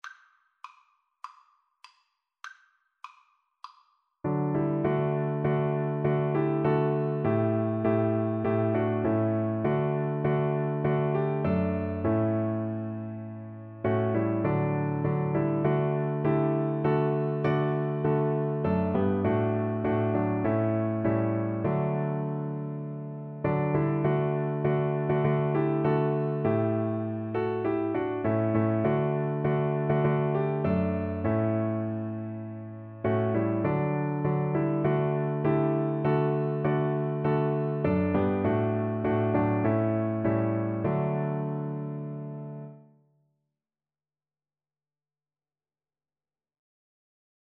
4/4 (View more 4/4 Music)
Traditional (View more Traditional Cello Music)